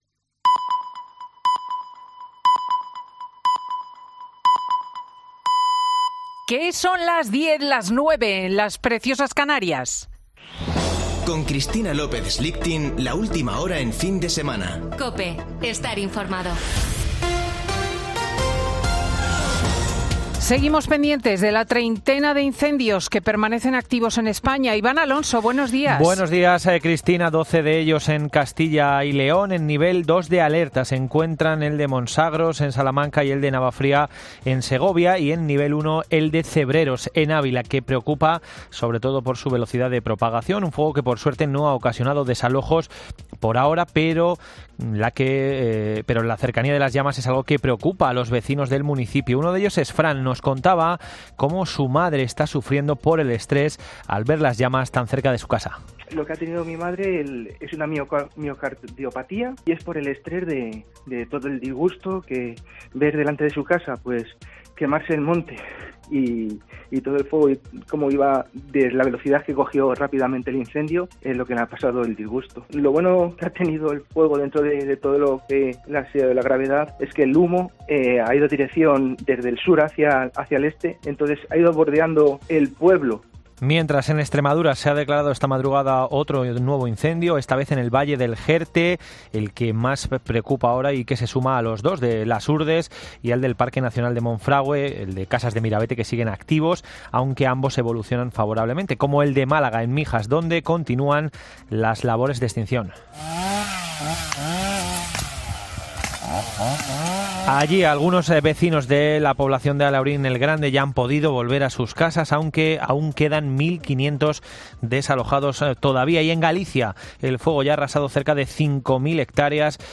Boletín de noticias de COPE del 17 de julio de 2022 a las 10:00 horas